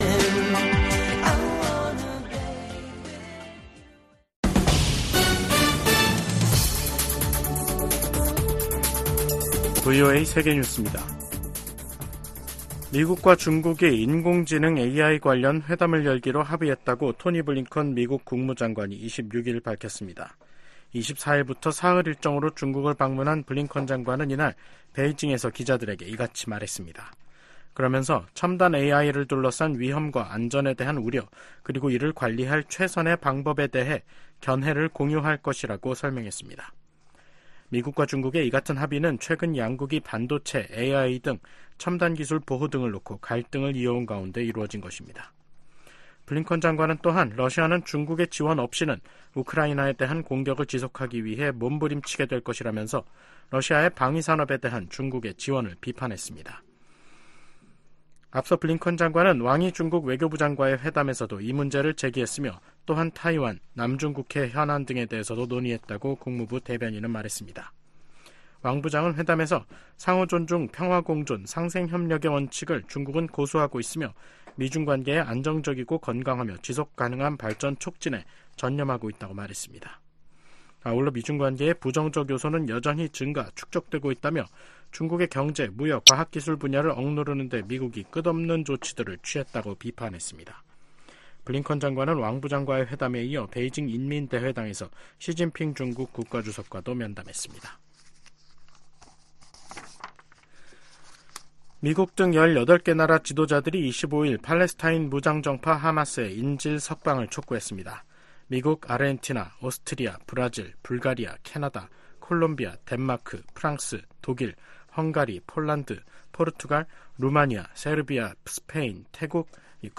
VOA 한국어 간판 뉴스 프로그램 '뉴스 투데이', 2024년 4월 26일 3부 방송입니다. 미국과 한국, 일본이 제14차 안보회의를 열고 지속적인 3국간 안보협력 의지를 재확인했습니다. 유엔 주재 미국 부대사는 중국과 러시아의 반대로 북한의 핵 프로그램에 대한 조사가 제대로 이뤄지지 못했다고 지적했습니다. 북한이 김정은 국무위원장이 참관한 가운데 신형 240mm 방사포탄 검수사격을 실시했습니다.